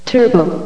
turbo.ogg